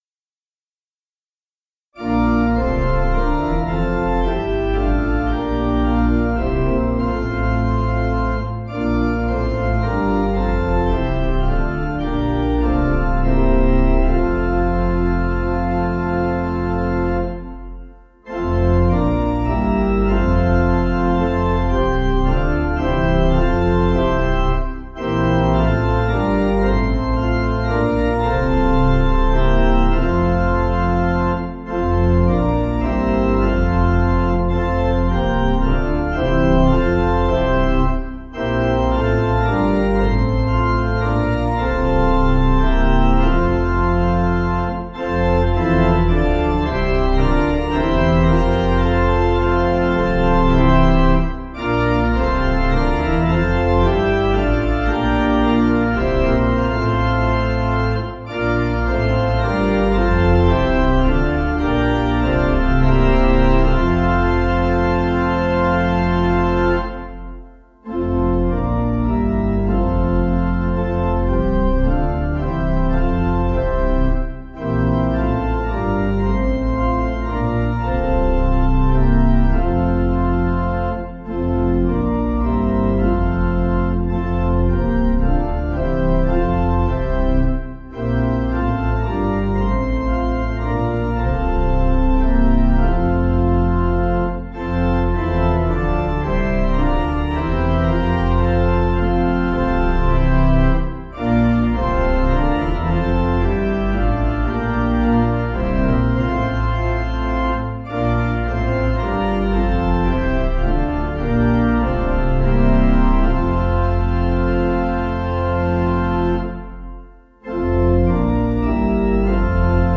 Swiss melody
Organ